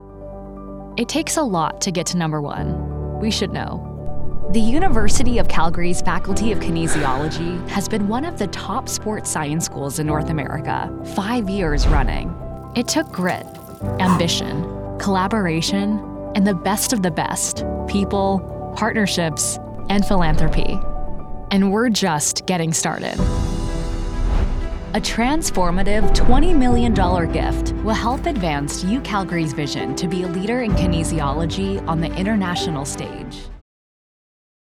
Erzählung
Meine Stimme reicht von einer gesprächigen, lässigen, freundlichen Gen Z über einen charmanten, nahbaren, coolen, komödiantischen besten Freund mit einem kleinen Krächzen bis hin zu einem energiegeladenen, neugierigen, furchtlosen, entzückenden kleinen Jungen.
Mikrofon: Sennheiser MKH 416
SENDEQUALITÄT IM HEIMSTUDIO